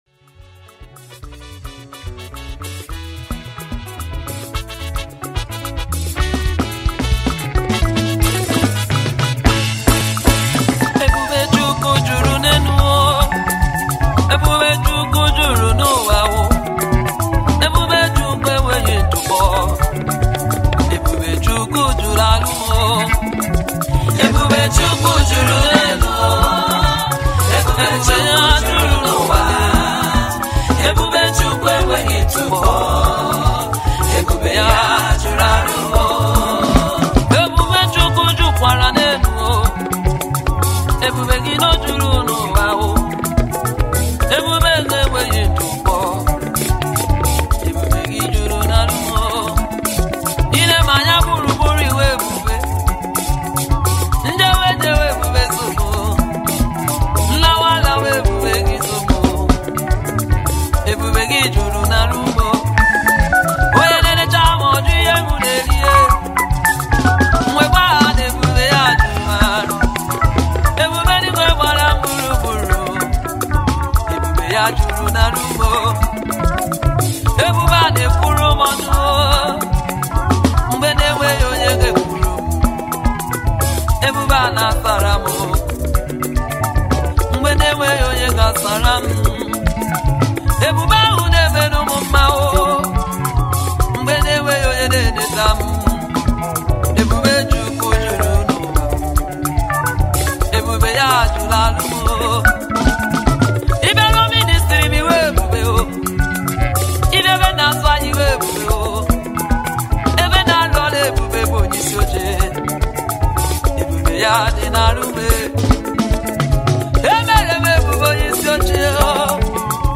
stands out as a soul-lifting gospel anthem.